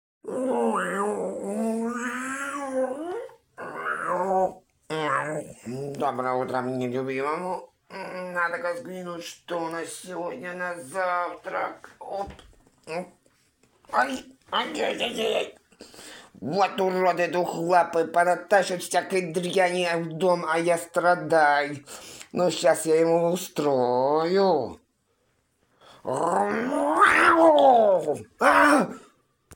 Озвучка Злой кот.m4a
Раздражение